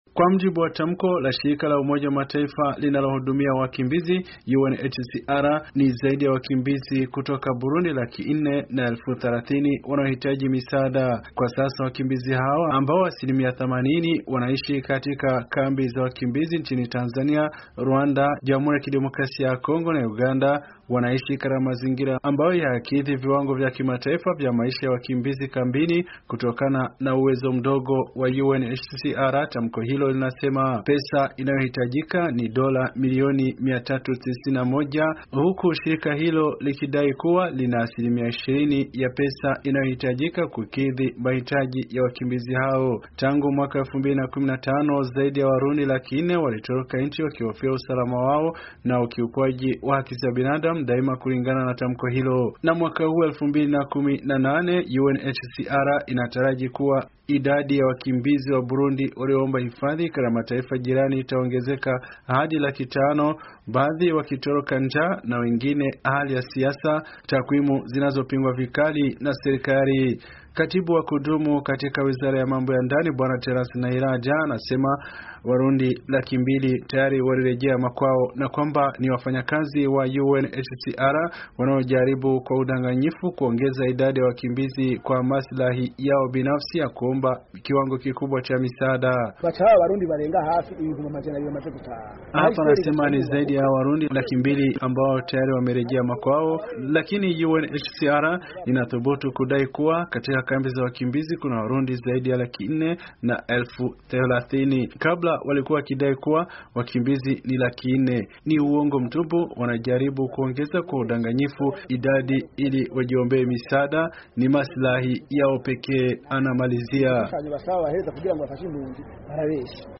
Ripoti